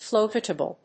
音節float・a・ble 発音記号・読み方
/flóʊṭəbl(米国英語), flˈəʊtəbl(英国英語)/